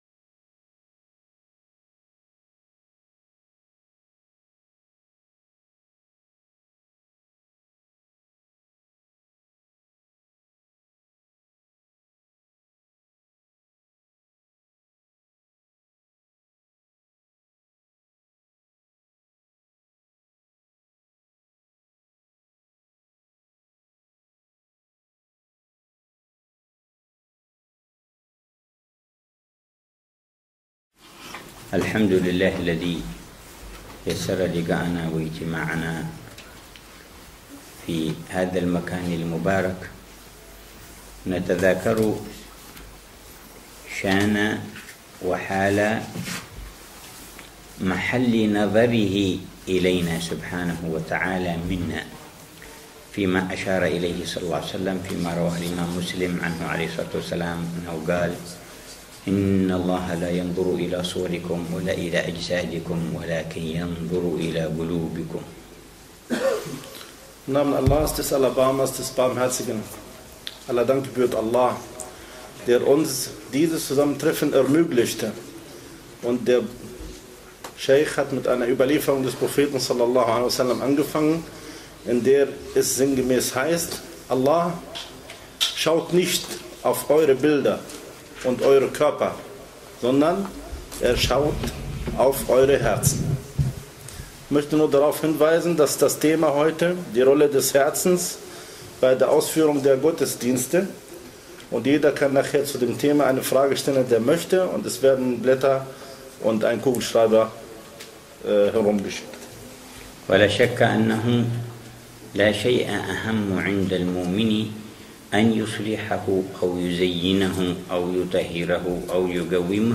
Der Vortrag des Gelehrten Shaikh Habib Omar bin Hafidh in Berlin w�hrend seines Deutschlandbesuchs -mit deutscher �bersetzung